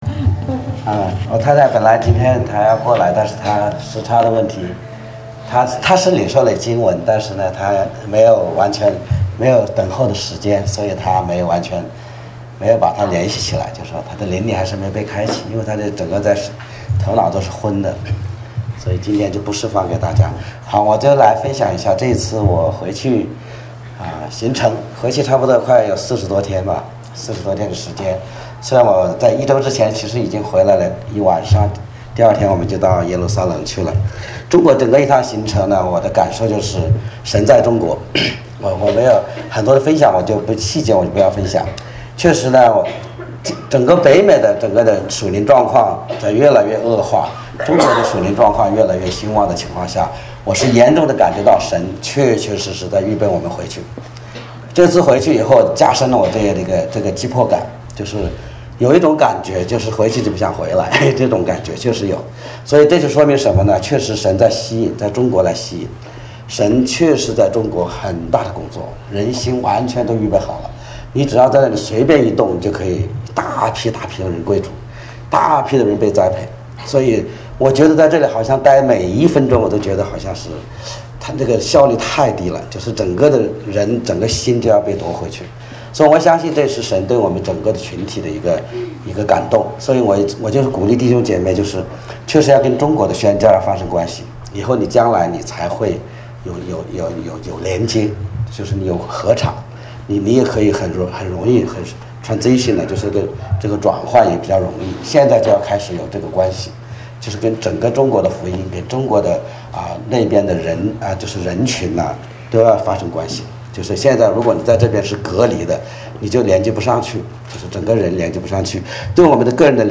正在播放：--2014年11月16日主日恩膏聚会（2014-11-16）